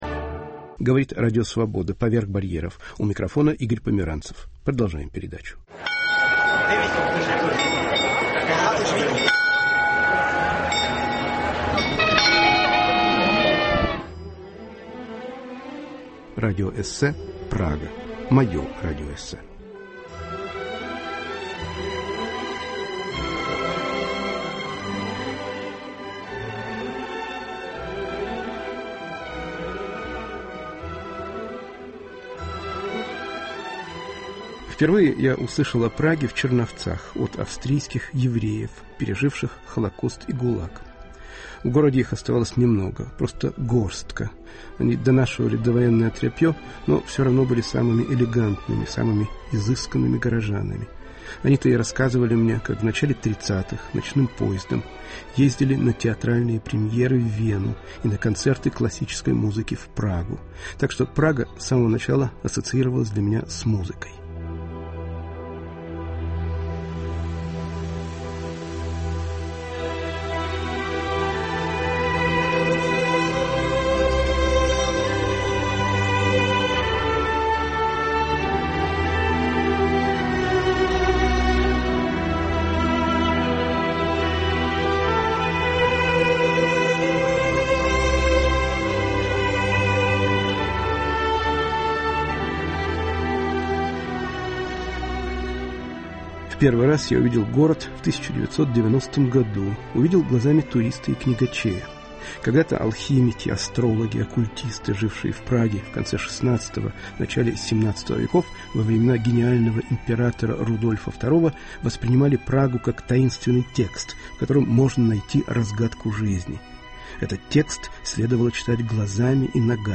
Радиоэссе о Праге